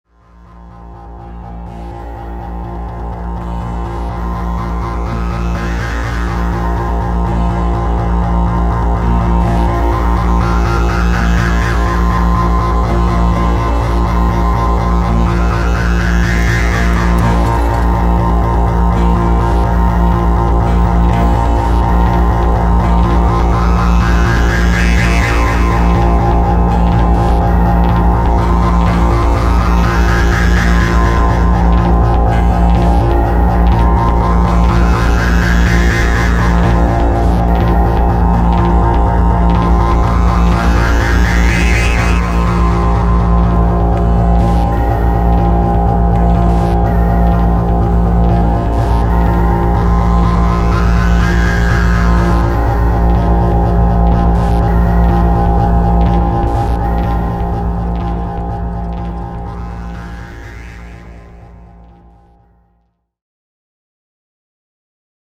electro-industrial